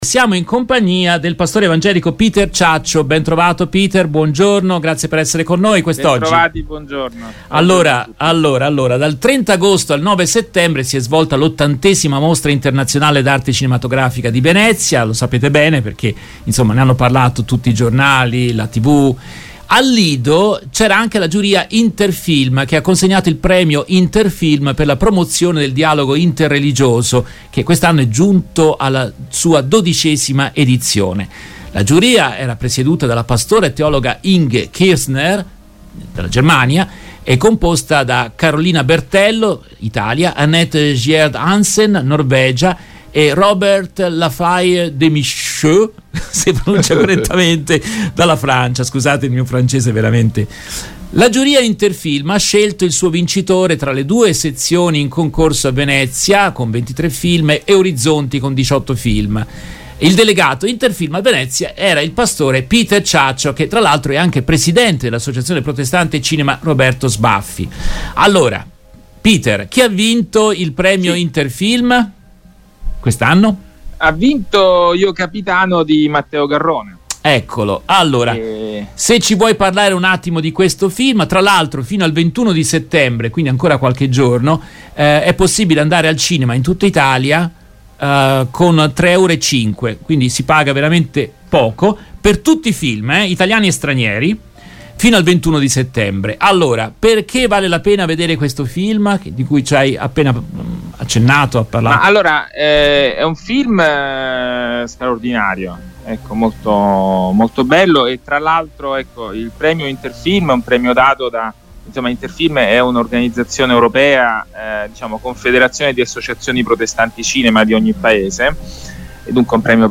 lo hanno intervistato nel corso della diretta RVS del 18 settembre.